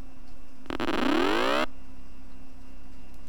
Thus the output was essentially a continuous-time chirp sampled at a rate of p*W. An LFM chirp has a constant magnitude of one, but its phase varies quadratically with respect to its displacement from the time origin.
LFM Chirp
LFM chirp....
chirp.au